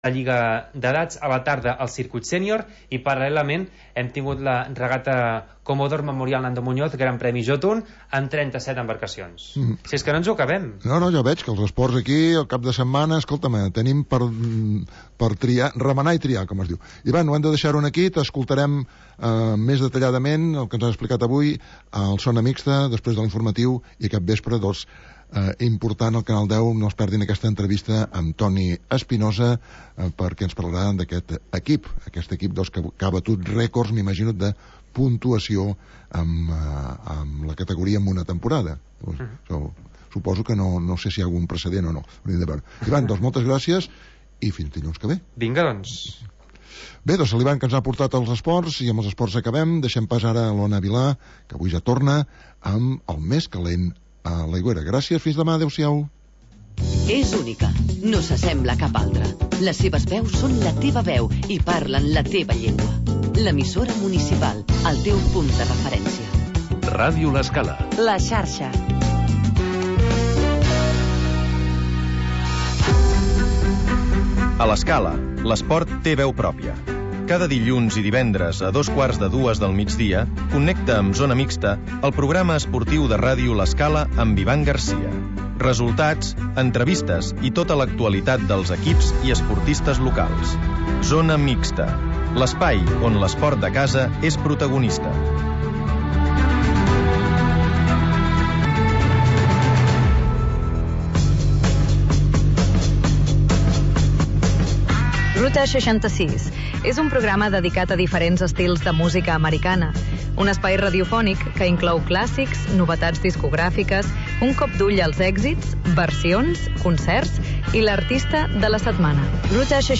Magazín d'entreteniment